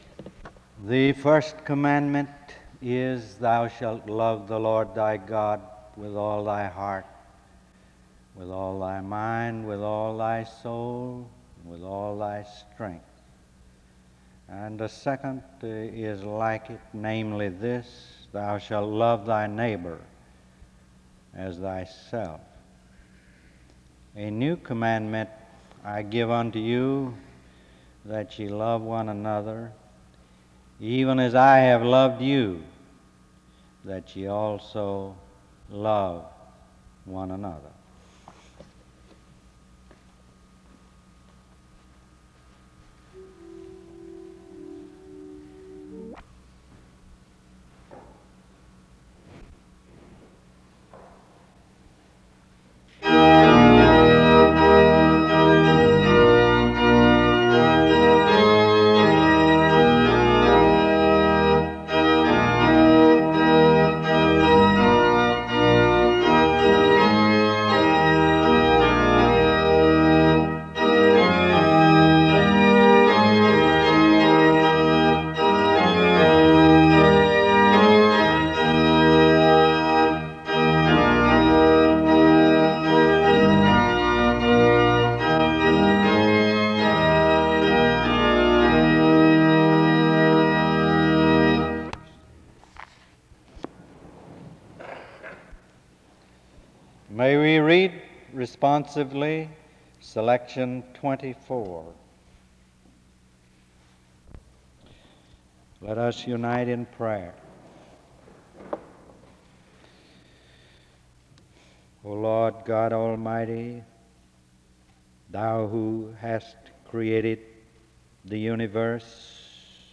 The service begins with a scripture reading of Matthew 22:37-39 from 0:00-0:38. Music plays from 0:42-1:32.
An introduction to the speaker is made from 4:22-5:48.
SEBTS Chapel and Special Event Recordings SEBTS Chapel and Special Event Recordings